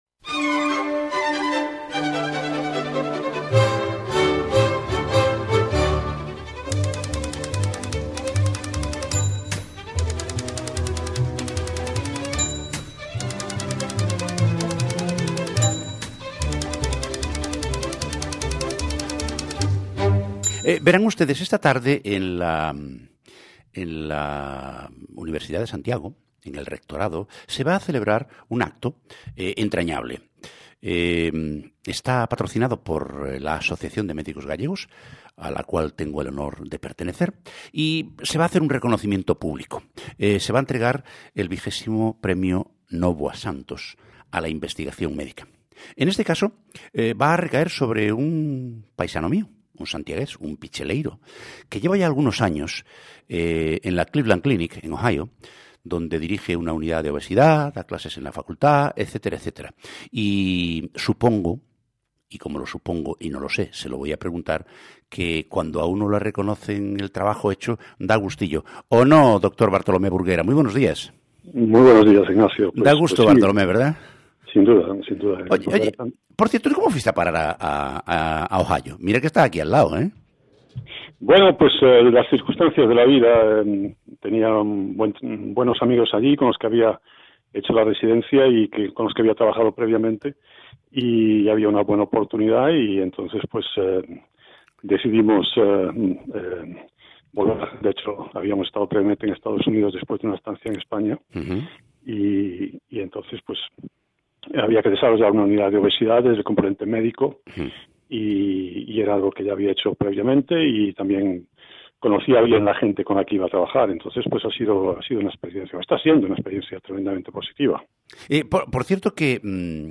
El calendario avanza inexorable y la cuenta atrás nos indica que quedan menos de dos meses para que arranque el II Encontro Mundial de Médicos Galegos.